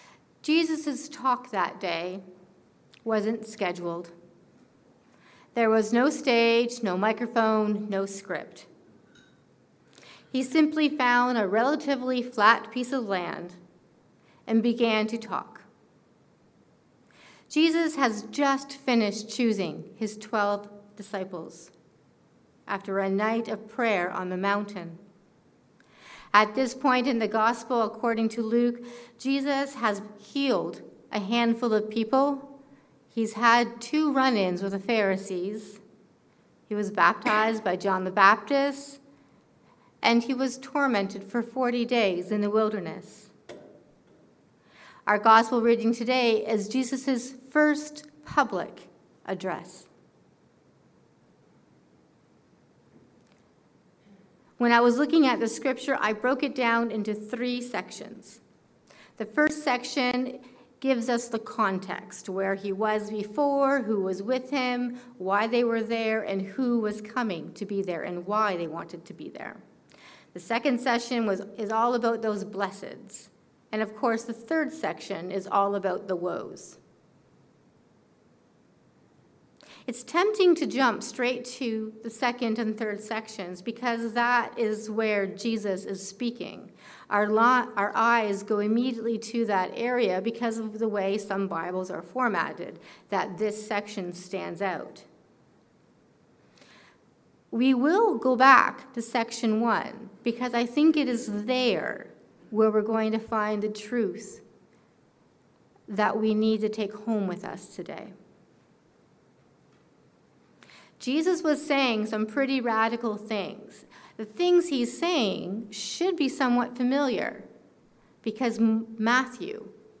In need of God. A sermon on Luke 6:17-26